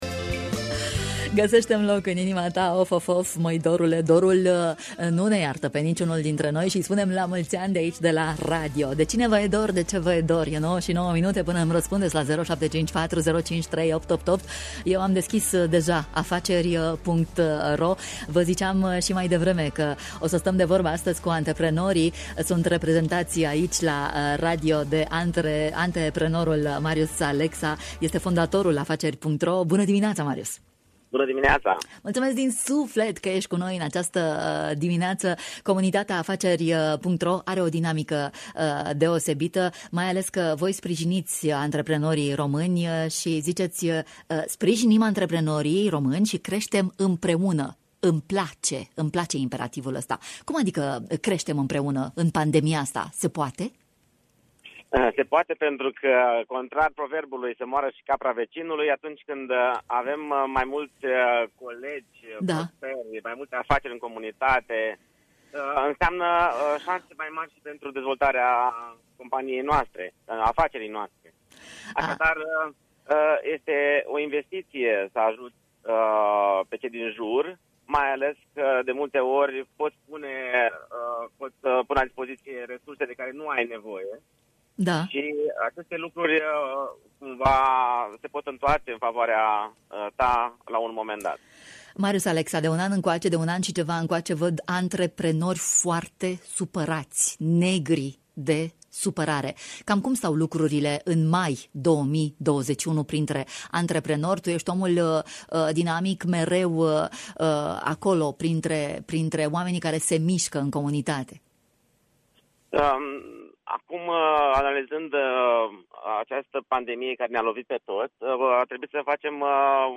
a stat de vorbă cu noi în matinal: